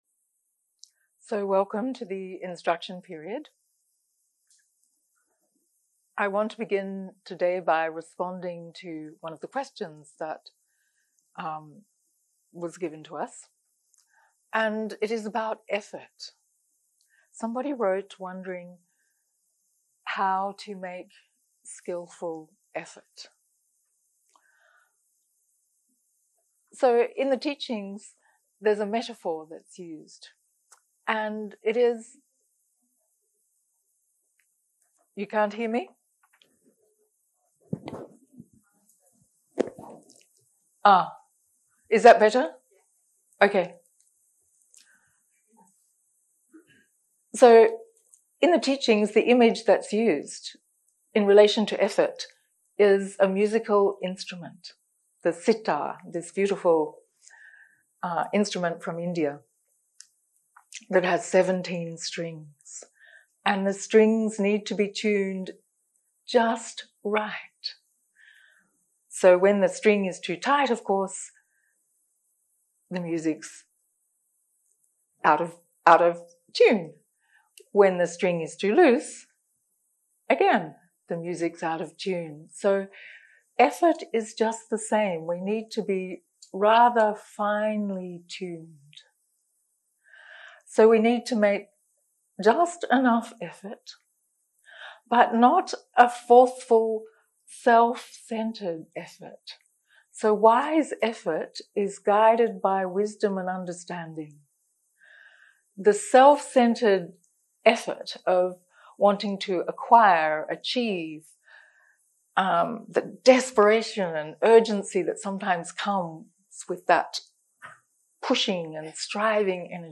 הקלטה 5 - יום 3 - בוקר - הנחיות למדיטציה - אנאפאנסטי סוטה חלק שני 0:00 0:00 סוג ההקלטה: סוג ההקלטה: שיחת הנחיות למדיטציה שפת ההקלטה: שפת ההקלטה: אנגלית